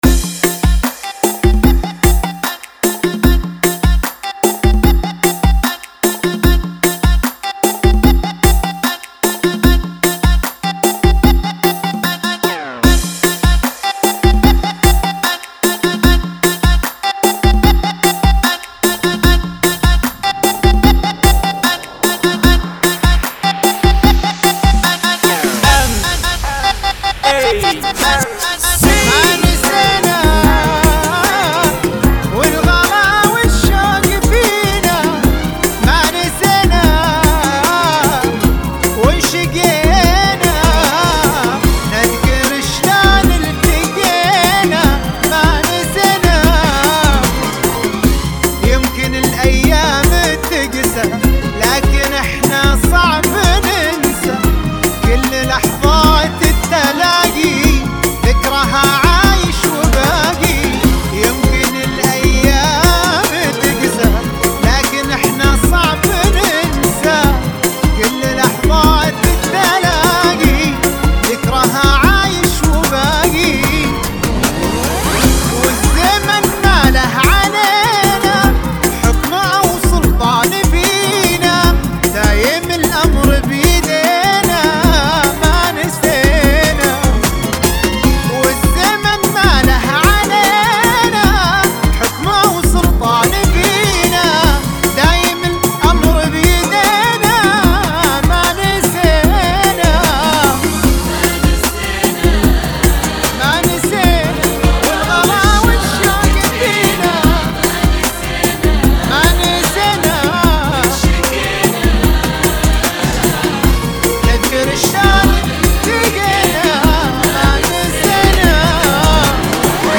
75 bpm
Funky